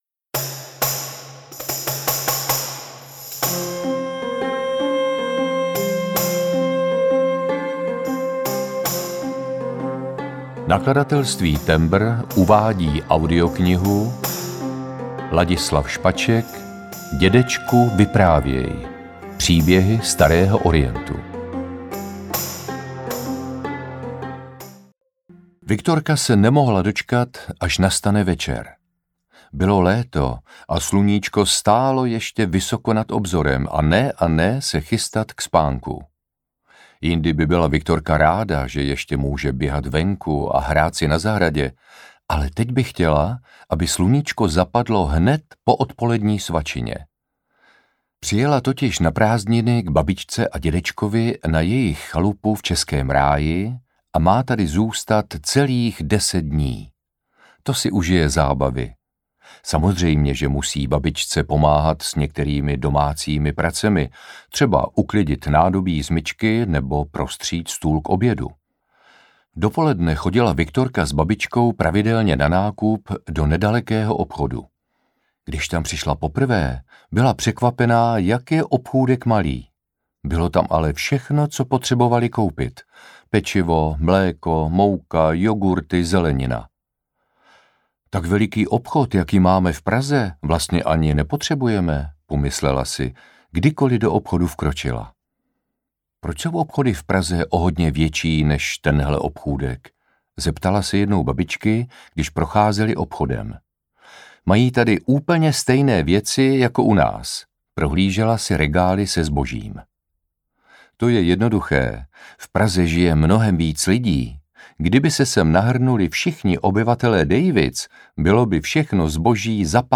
Dědečku, vyprávěj – Příběhy starého Orientu audiokniha
Ukázka z knihy
• InterpretLadislav Špaček